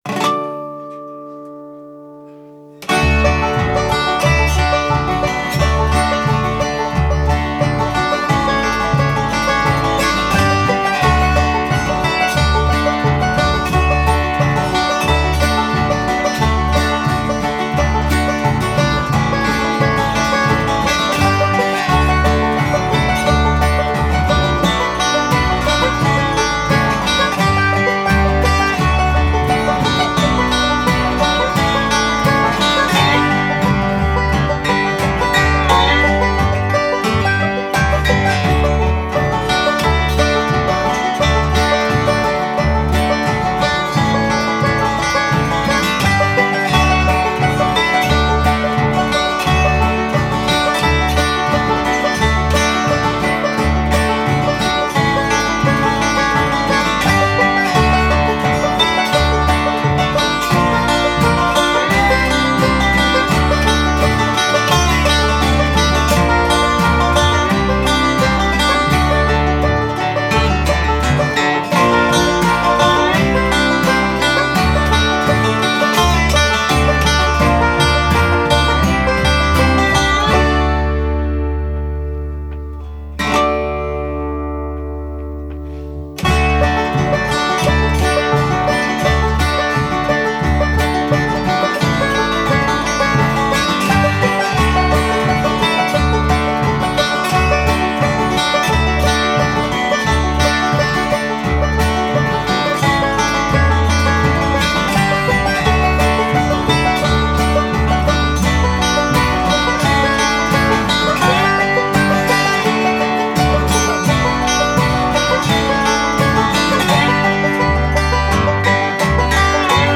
Genre: Americana.